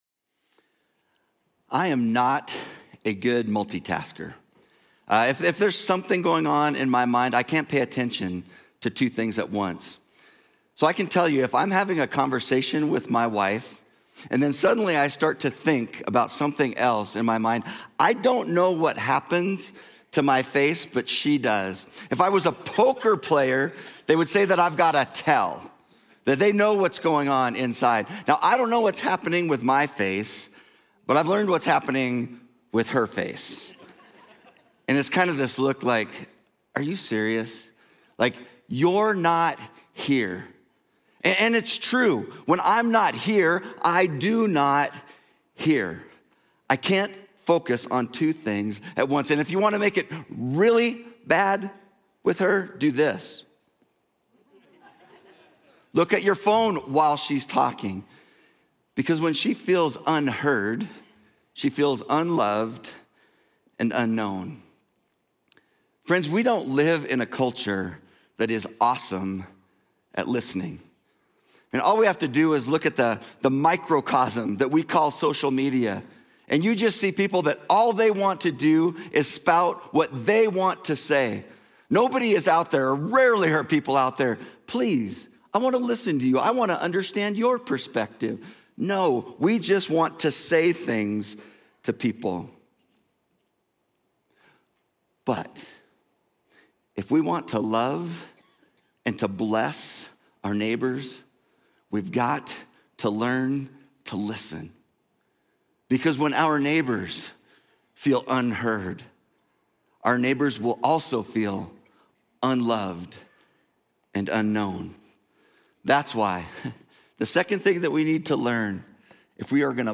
Journey Church Bozeman Sermons Bless: Listen & Eat May 18 2025 | 00:42:22 1x 00:00 / 00:42:22 Subscribe Share Apple Podcasts Overcast RSS Feed Share Link Embed